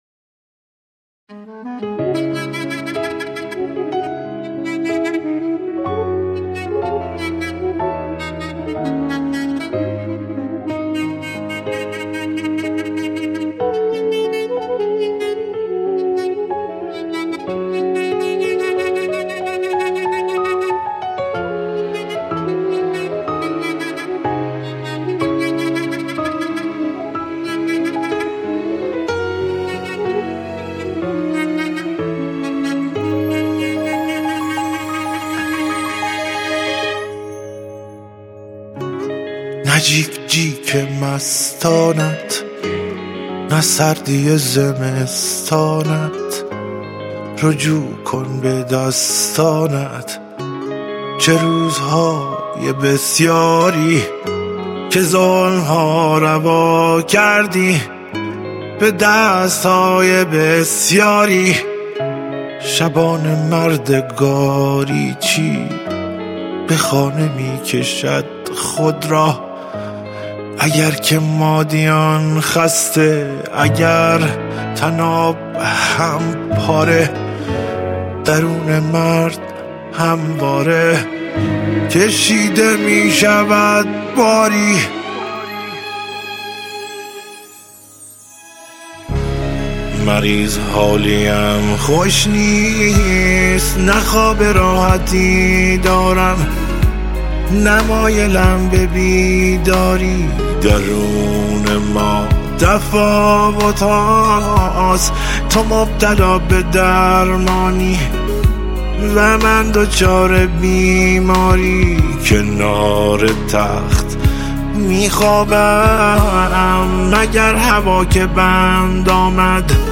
غمگین
• آهنگ تیتراژ